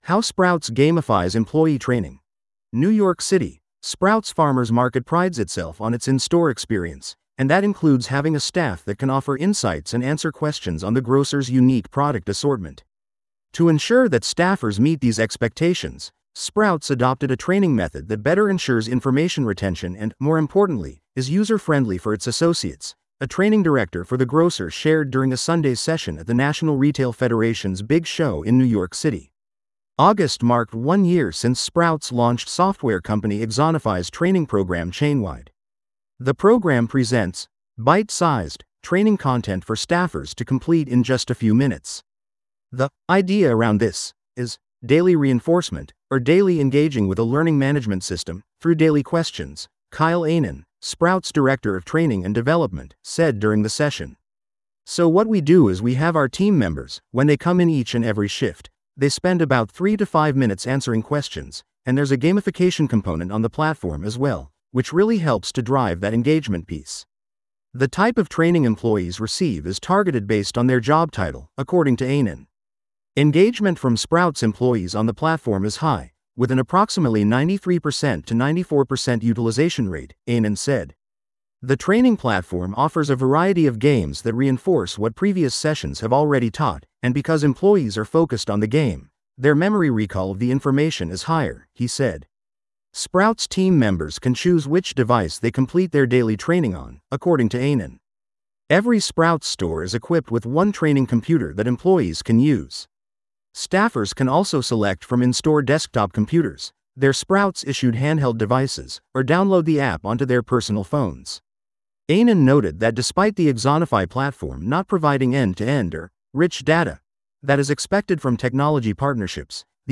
This audio is auto-generated.